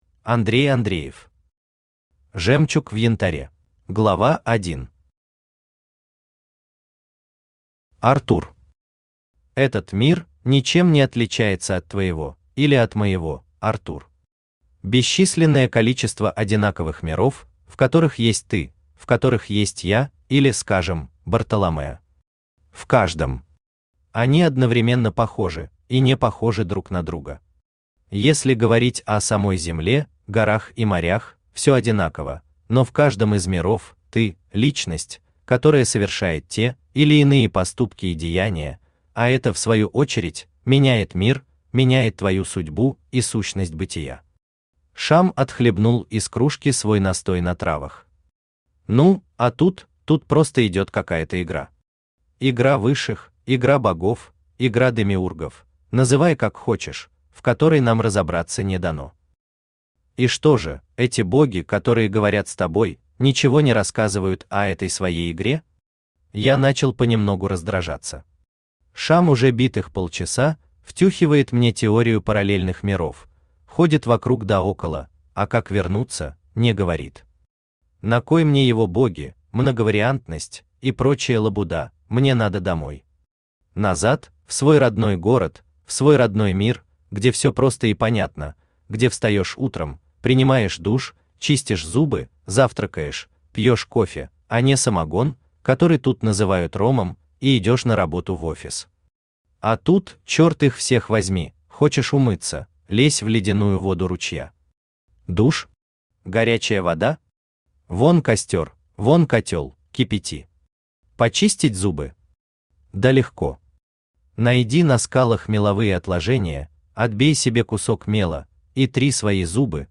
Жемчуг в янтаре (слушать аудиокнигу бесплатно) - автор Андрей Владимирович Андреев
Читает: Авточтец ЛитРес